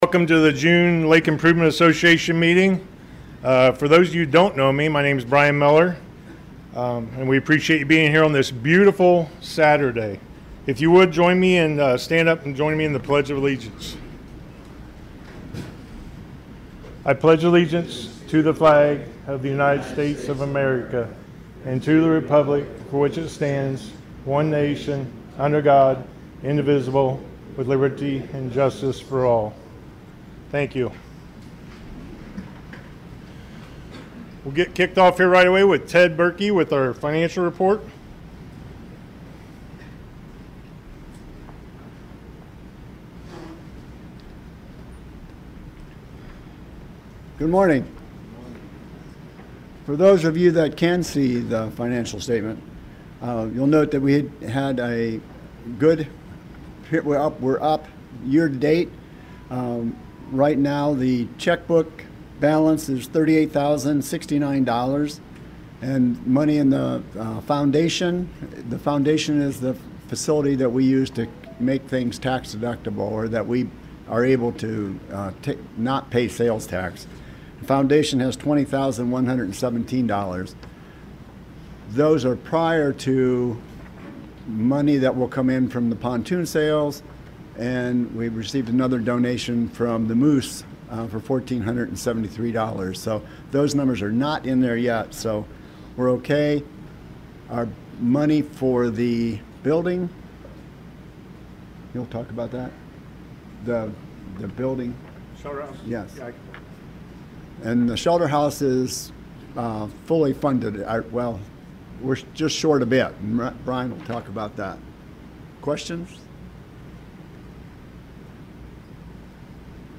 Lake Improvement Association holds June Meeting-Click for a summary and to listen The Lake Improvement Association held its June Meeting on Saturday Morning June 7th at the Moose in Celina.